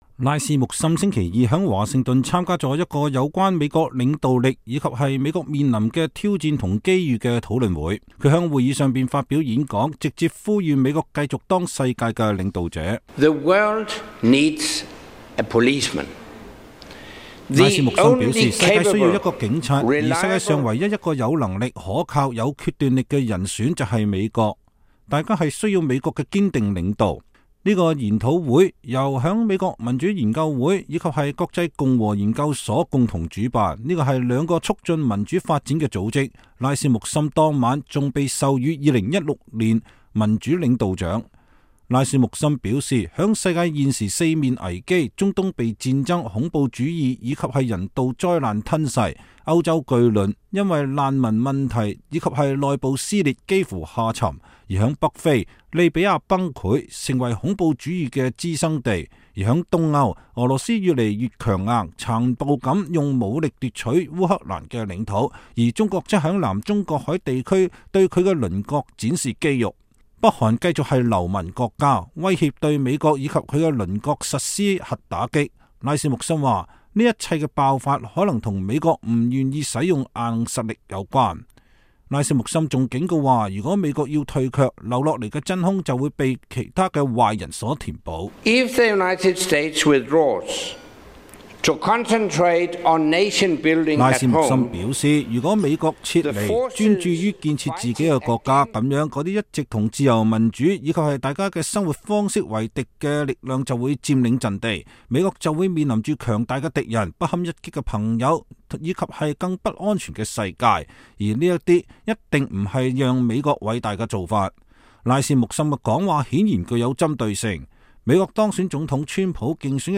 前北約秘書長安德斯·福格·拉斯穆森星期二在美國民主研究會和國際共和研究所共同主辦的研討會上發表演講。